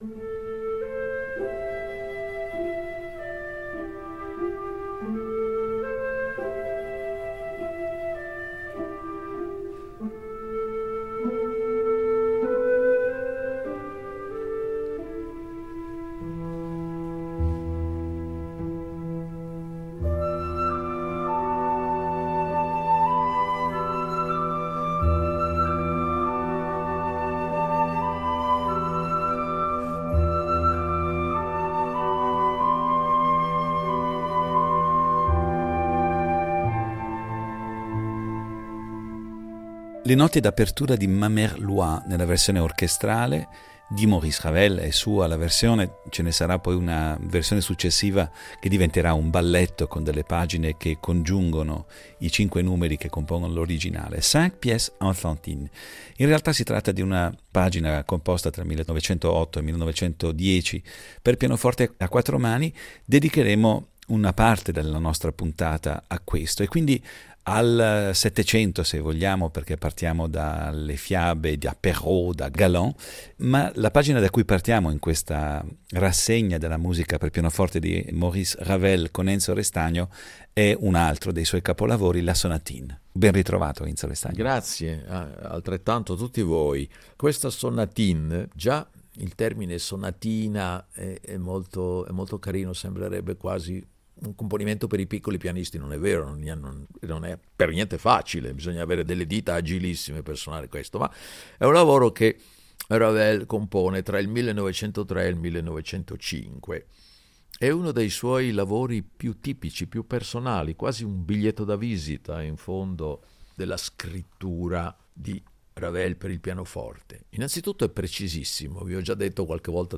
Questa quinta puntata si apre con le prime battute della versione orchestrale di “Ma Mère l'Oye”.